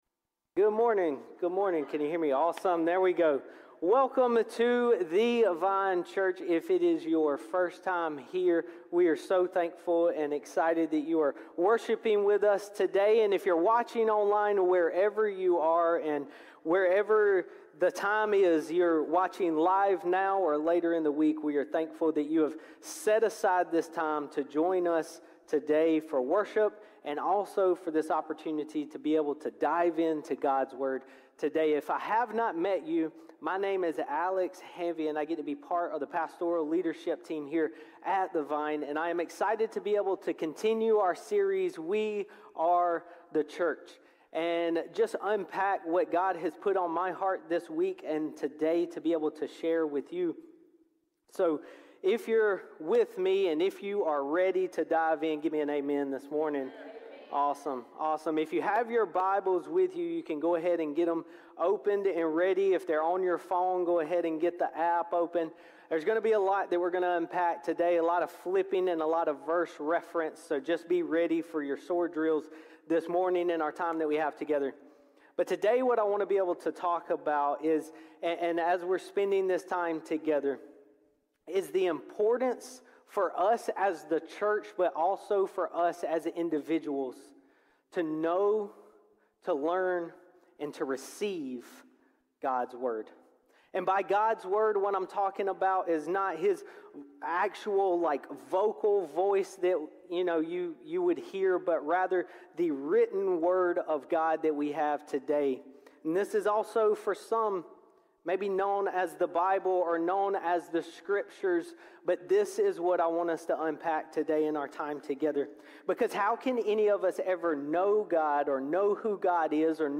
Sermons | The Vine Church